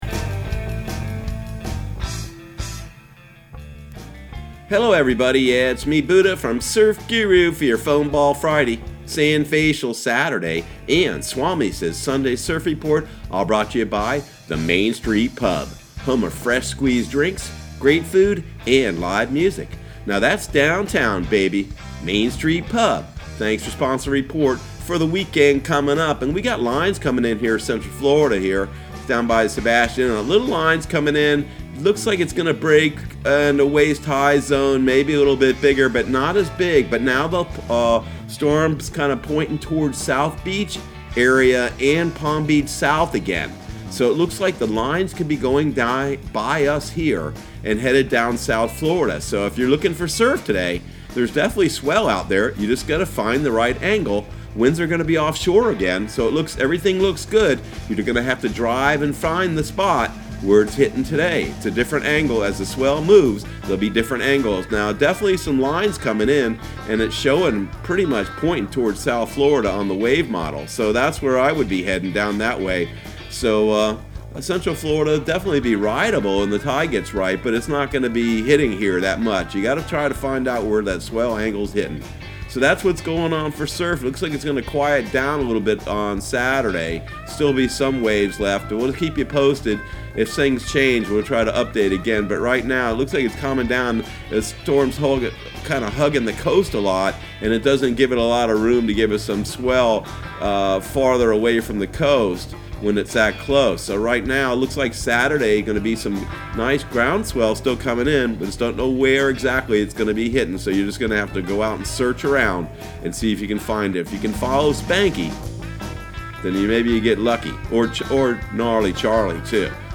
Surf Guru Surf Report and Forecast 09/06/2019 Audio surf report and surf forecast on September 06 for Central Florida and the Southeast.